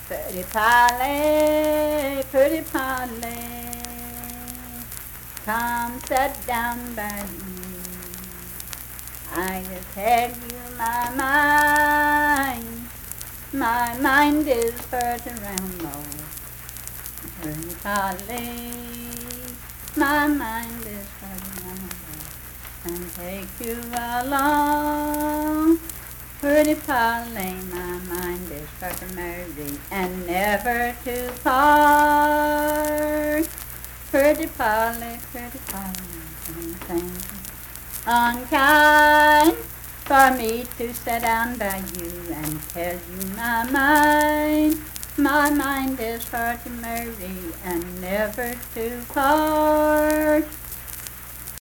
Unaccompanied vocal music
Performed in Big Creek, Logan County, WV.
Voice (sung)